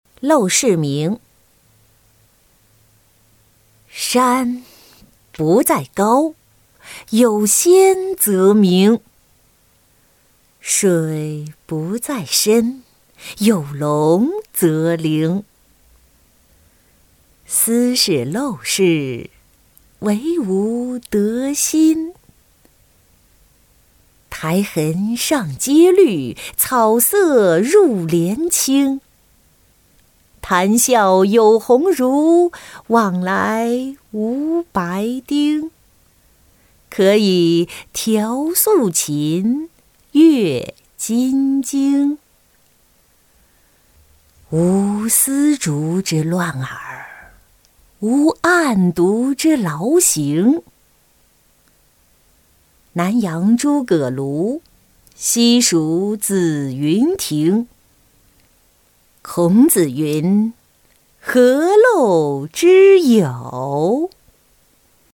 贾生-音频朗读